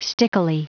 Prononciation du mot : stickily